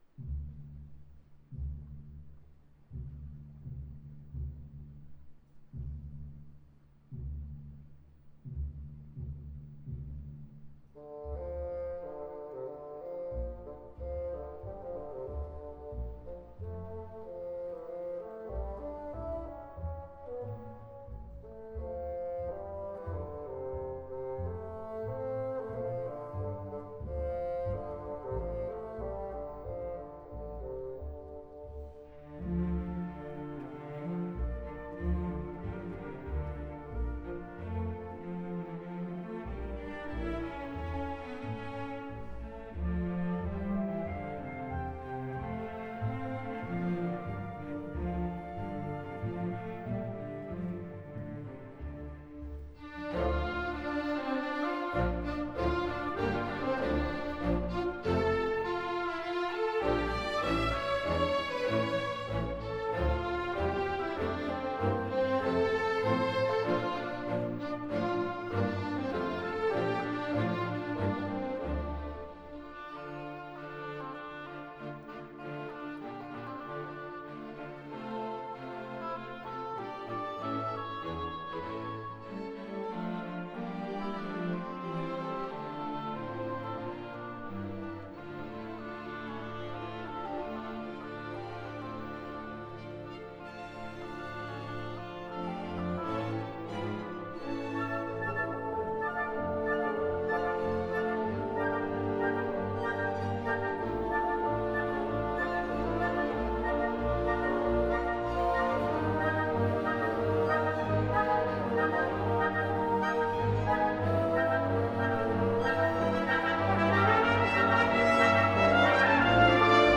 Period: 20th Century
Genre: Variations